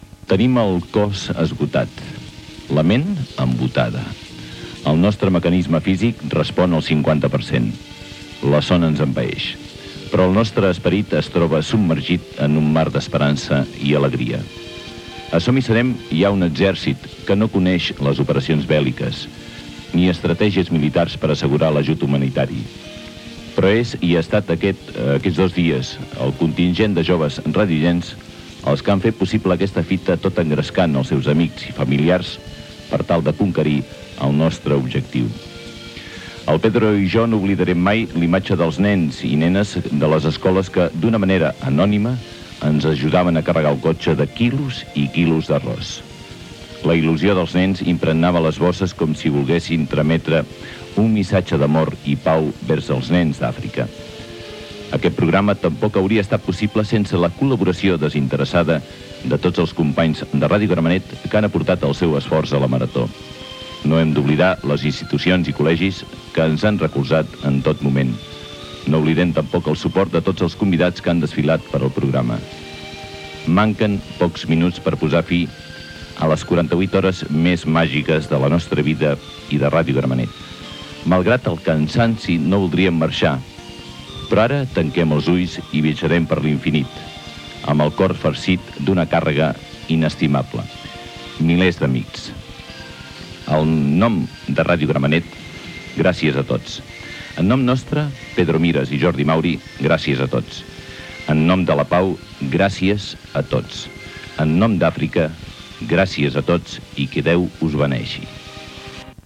Últimes paraules després de la marató radiofònica de 48 hores per recollir arròs a favor de la campanya de SOS Àfrica.
FM